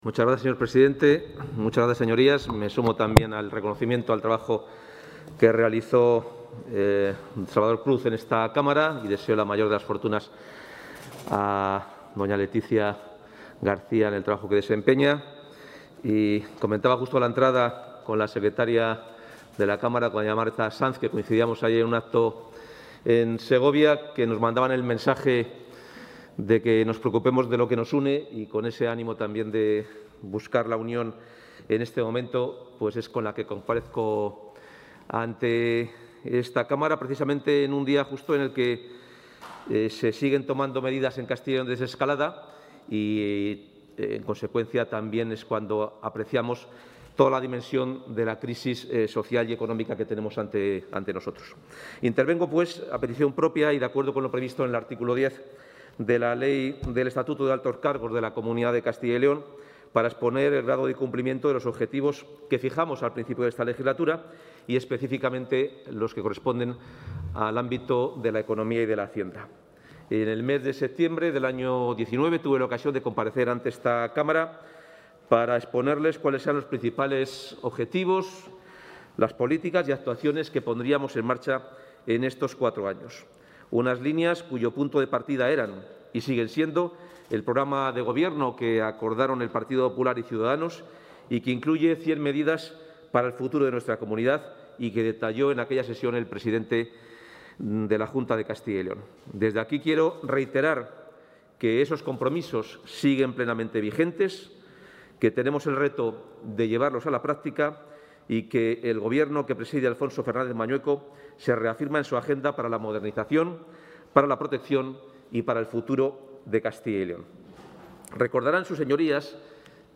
Comparecencia del consejero de Economía y Hacienda.
El consejero de Economía y Hacienda, Carlos Fernández Carriedo, ha manifestado hoy durante su intervención en la Comisión de Economía y Hacienda de las Cortes de Castilla y León, donde ha comparecido a petición propia para exponer el grado de cumplimiento de los objetivos de legislatura, que la Comunidad está ante un periodo de enormes desafíos ante los que se plantea un trabajo en común para mejorar la vida de los castellanos y leoneses.